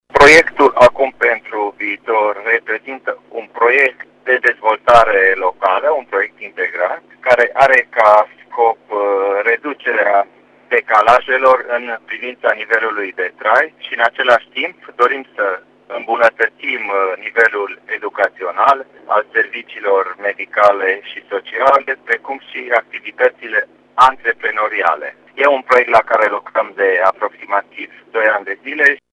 Primarul municipiului Târnăveni, Sorin Megheșan: